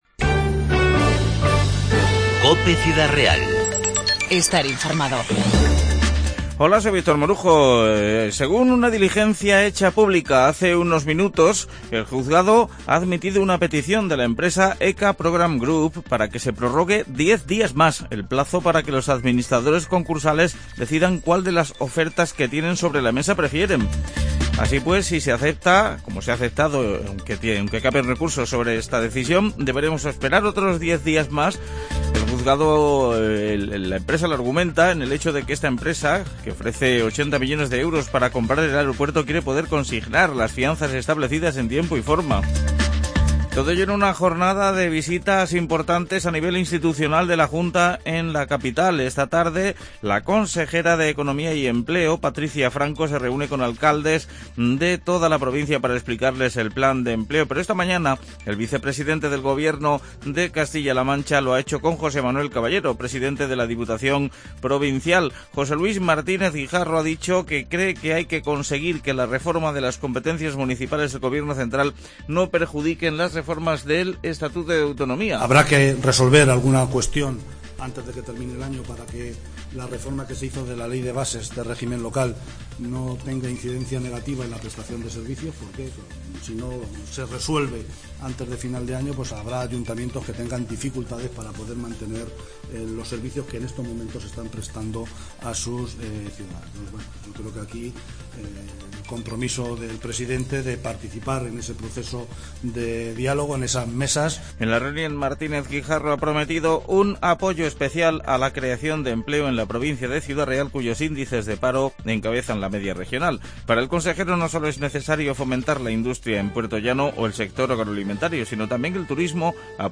Informativo 7-10-15